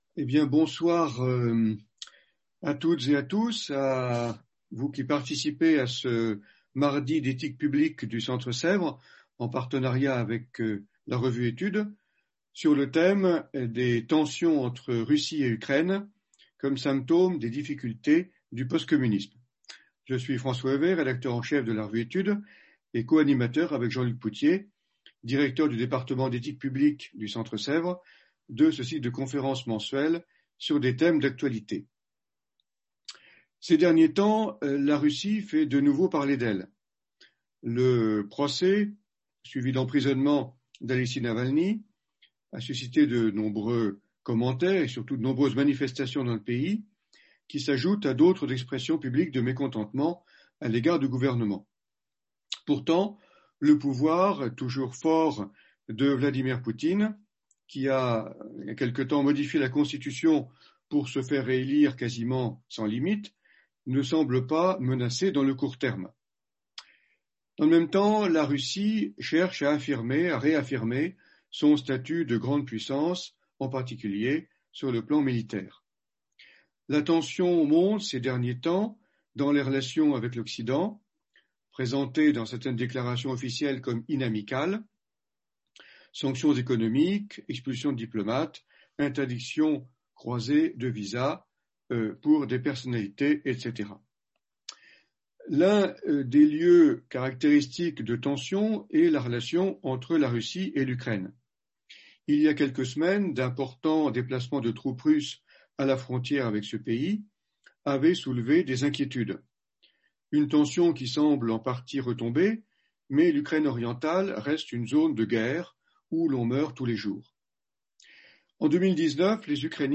Conférence des mardis d’éthique publique du 04 mai 2021